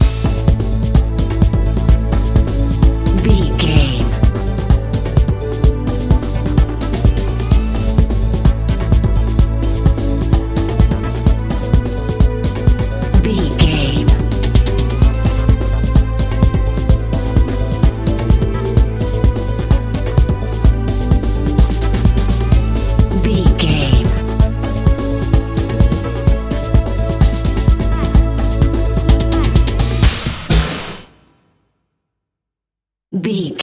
Epic / Action
Aeolian/Minor
Fast
drum machine
synthesiser
strings